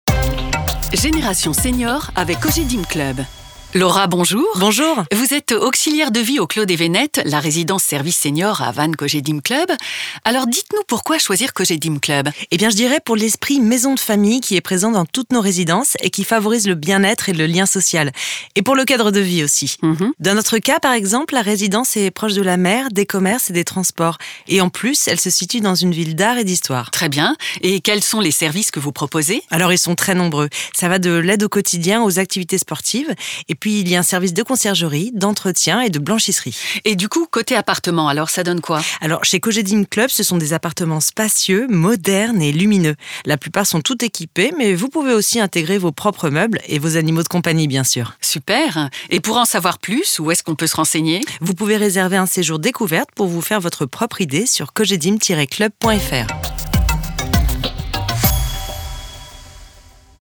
Chronique radio Génération Séniors
Voix off